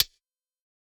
Index of /musicradar/ultimate-hihat-samples/Hits/ElectroHat B
UHH_ElectroHatB_Hit-19.wav